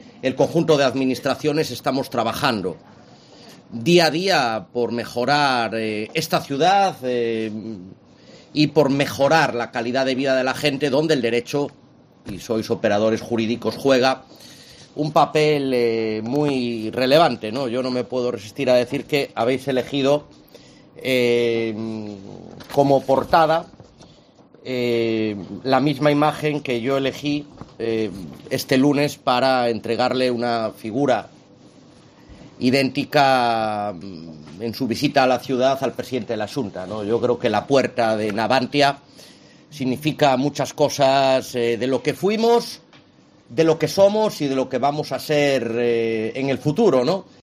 José Manuel Rey Varela, alcalde de Ferrol, durante la inauguración de las jornadas